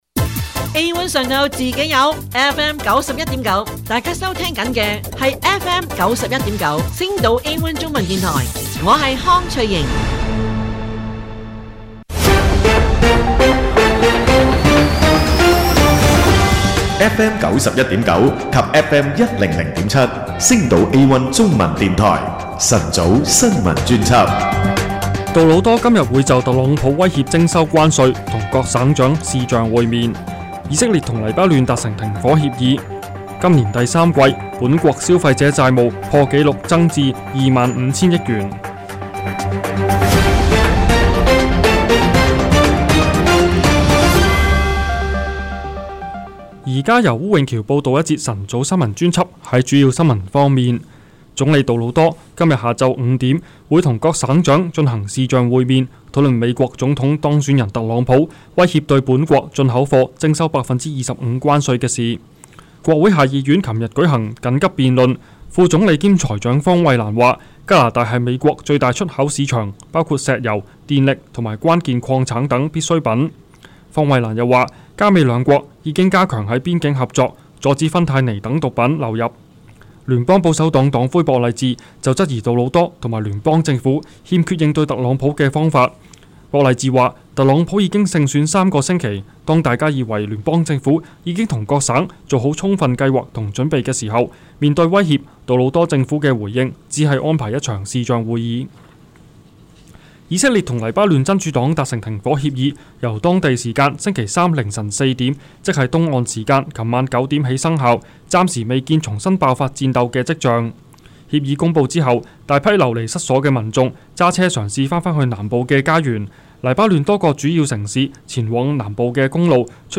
【有聲新聞】星島A1中文電台 晨早新聞專輯